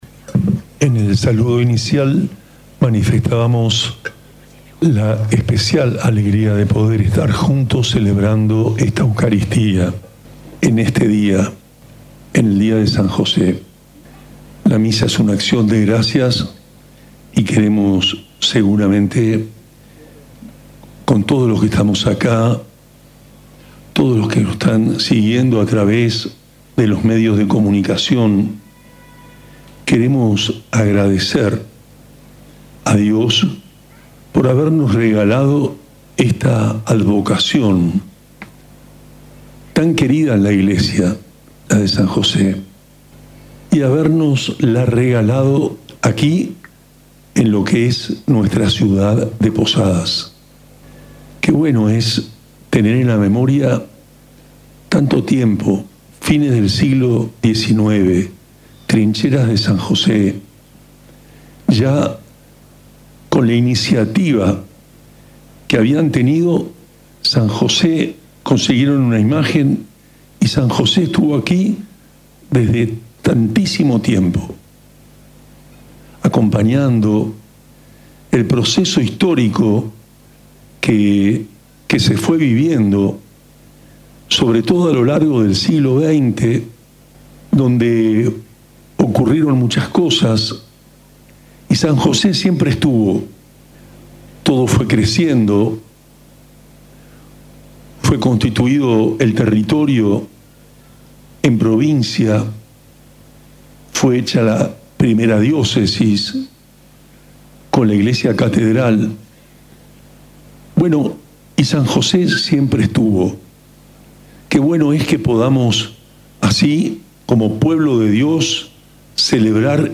La Eucaristía, celebrada a las 20 horas en la Iglesia Catedral, estuvo precedida por una multitudinaria procesión que recorrió las calles céntricas y culminó en un templo colmado de fieles.
homilia-monsenor-san-jose-2026.mp3-online-audio-converter.com_.mp3